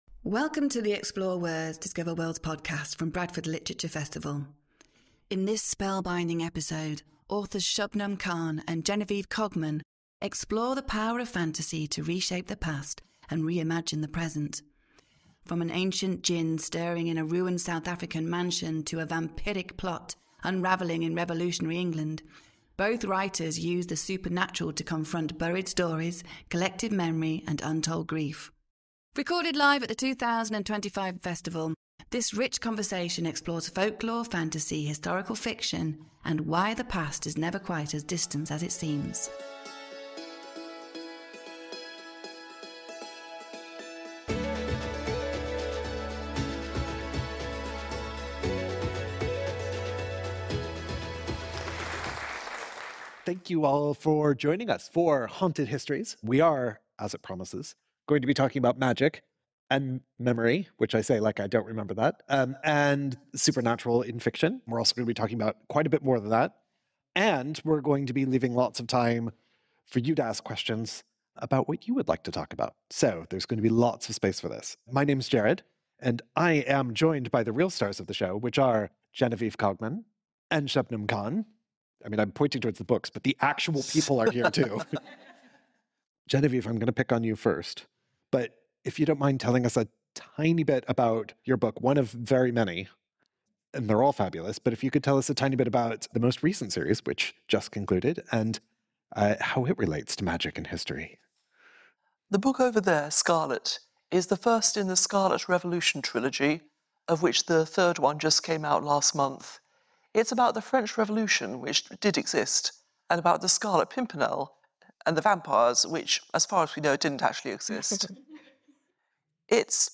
From an ancient djinn stirring in a ruined South African mansion to a vampiric plot unravelling in revolutionary England, both writers use the supernatural to confront buried stories, collective memory, and untold grief. Join them for a rich conversation on folklore, fantasy, historical fiction — and why the past is never quite as distant as it seems.